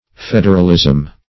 Federalism \Fed"er*al*ism\, n. [Cf. F. f['e]d['e]ralisme.]